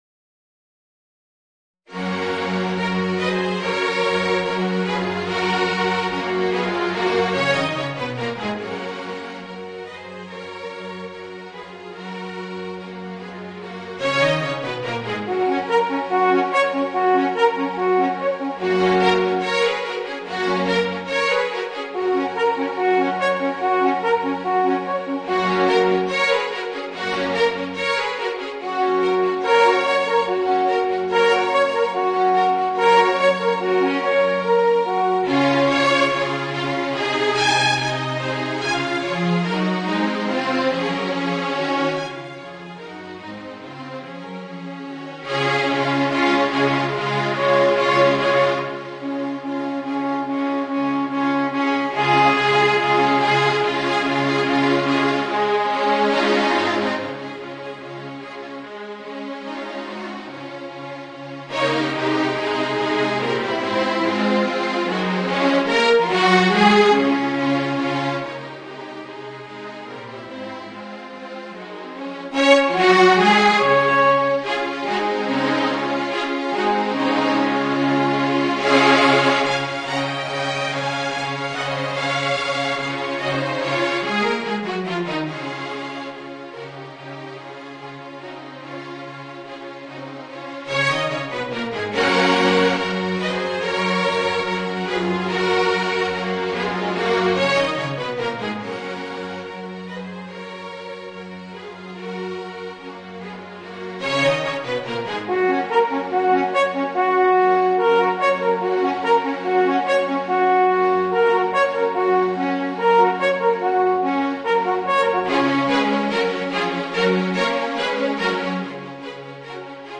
Voicing: Alphorn and String Orchestra